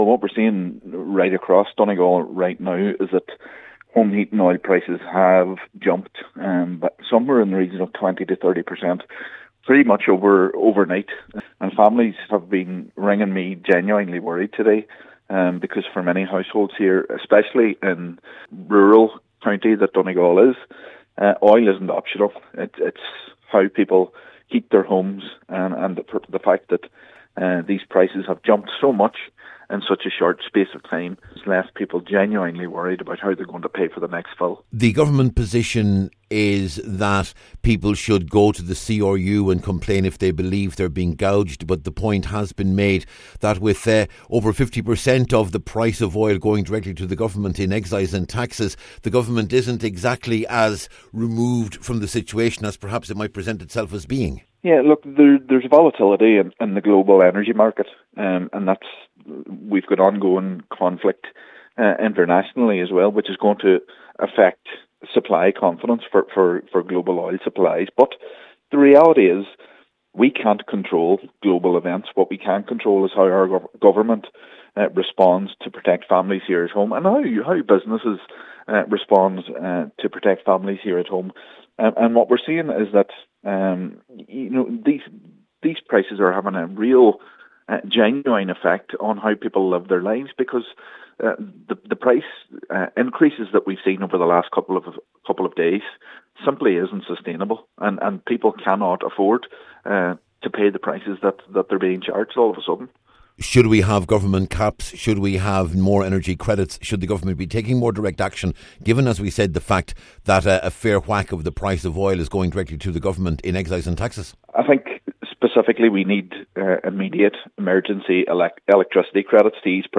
Donegal Councillor Gary Doherty says that’s not enough, and he’s calling for the reintroduction of an energy credit.